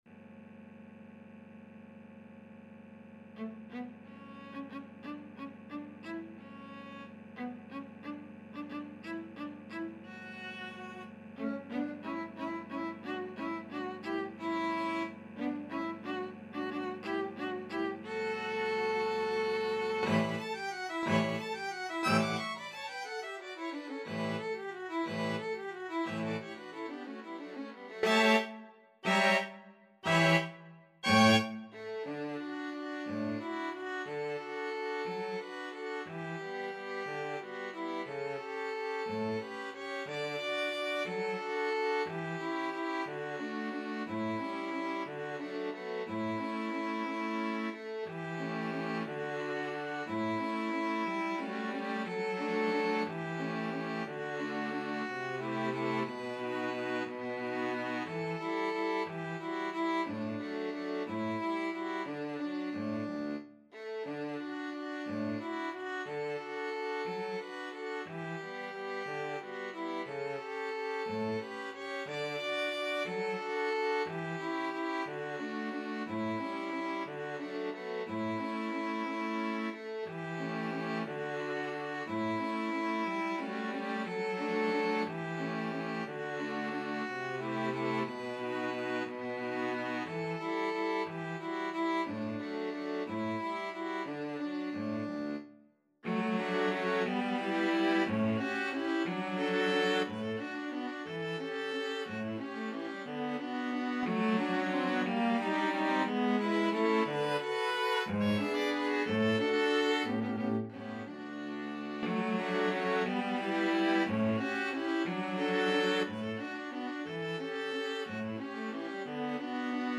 Violin 1Violin 2ViolaCello
3/4 (View more 3/4 Music)
Tempo di Valse .=c.60
Classical (View more Classical String Quartet Music)